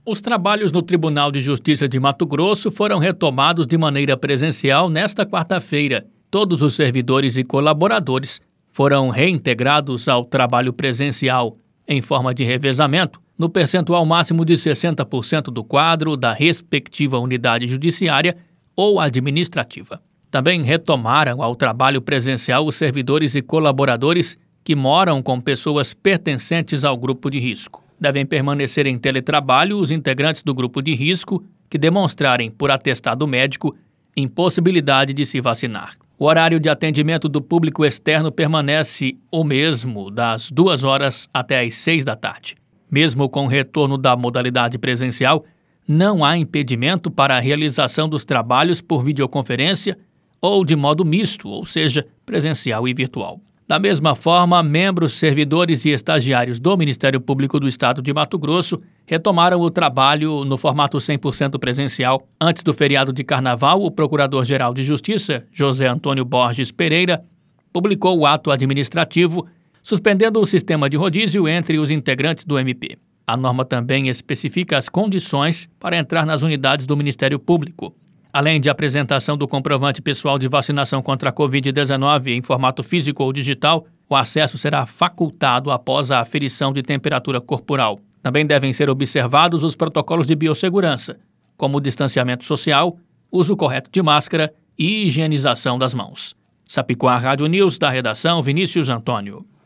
Boletins de MT 03 mar, 2022